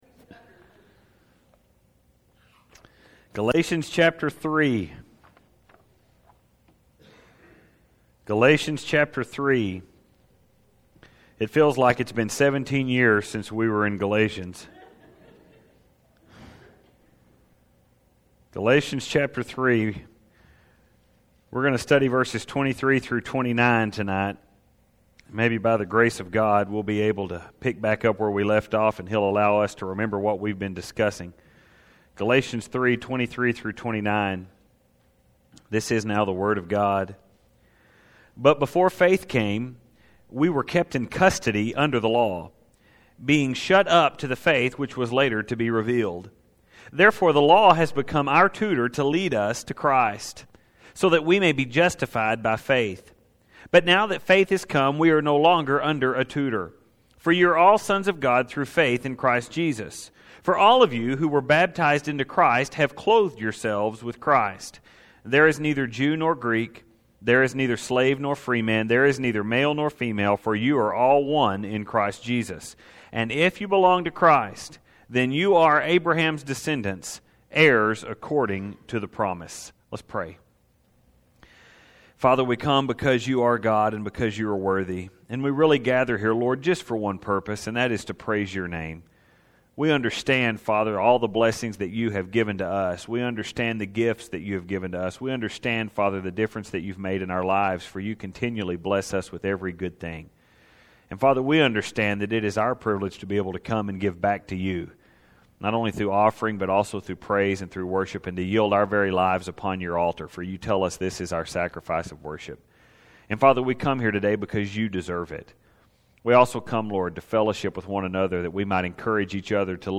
Getting these sermons from Galatians about every other week.